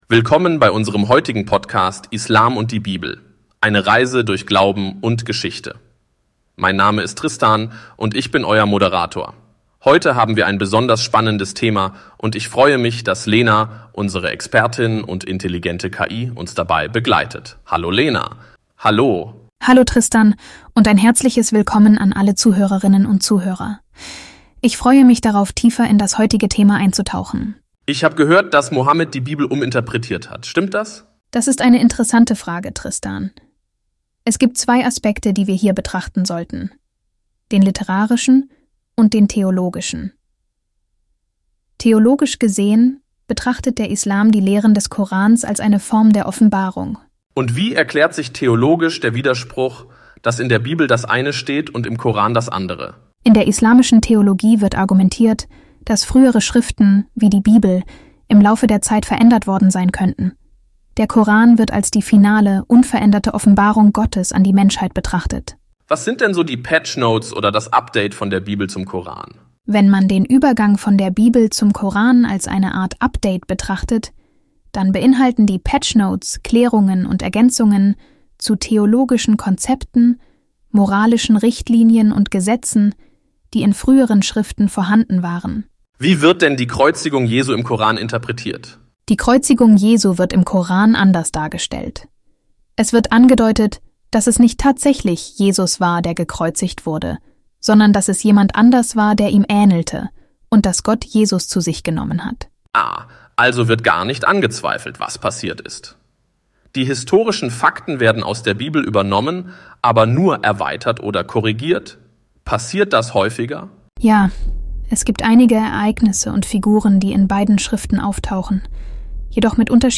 Dies und vieles mehr erwartet Sie in einer Diskussion, die sowohl informiert als auch zum Nachdenken anregt.